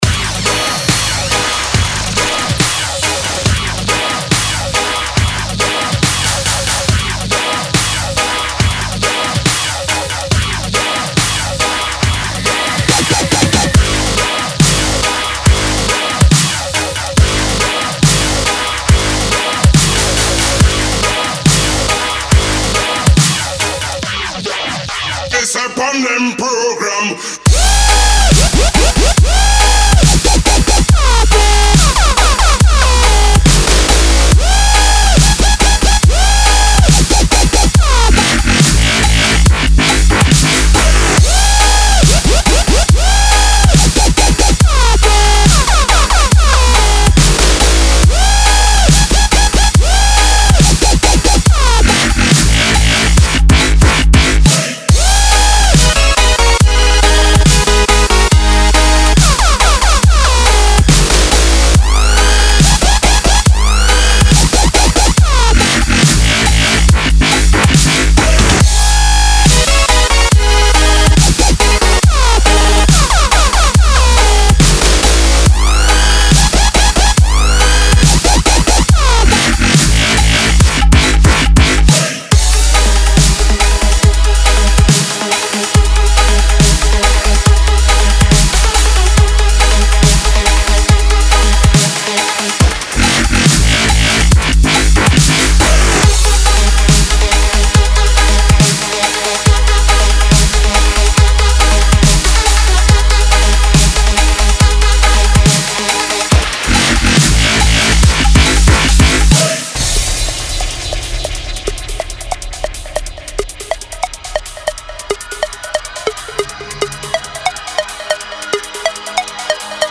Категория: Dubstep